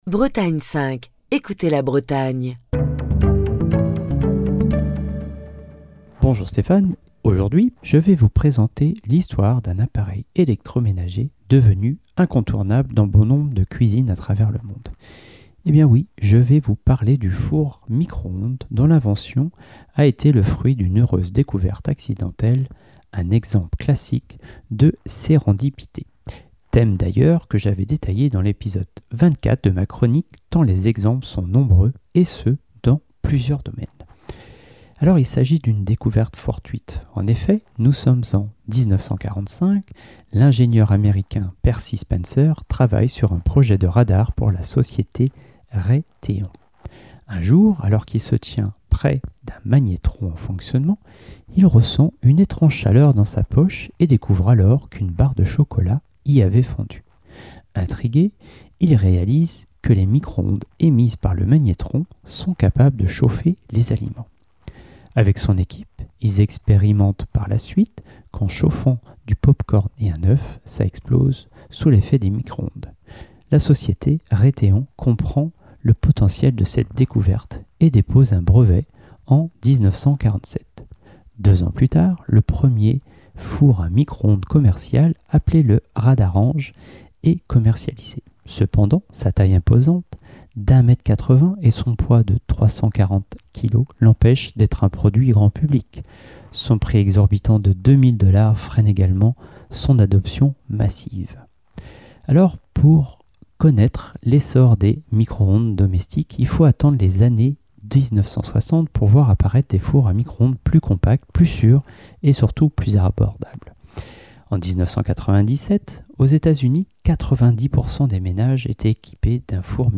Chronique du 19 juin 2024. Il est des objets que nous utilisons tous les jours, sans parfois même y prêter attention, tellement ils font partie de notre quotidien...